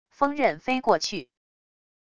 风刃飞过去wav音频